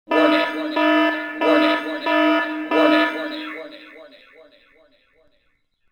SelfDestructStart.wav